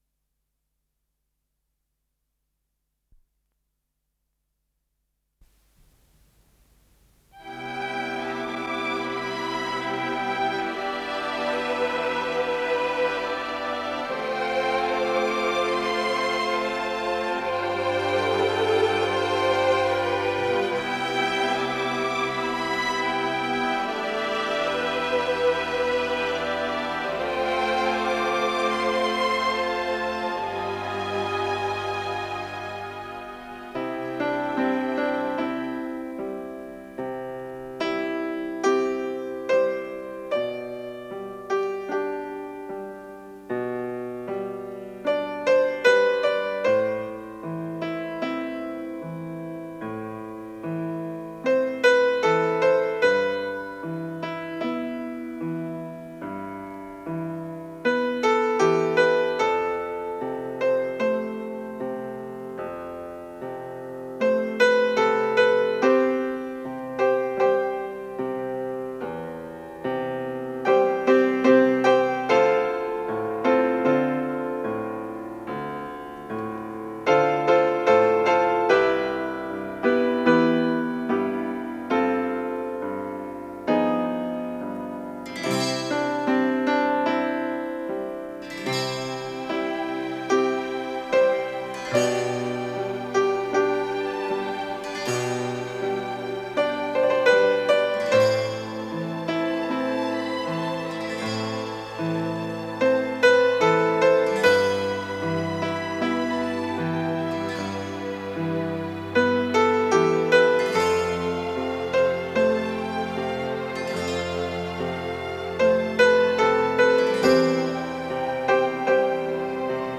с профессиональной магнитной ленты
Соло на фортепиано
ВариантДубль моно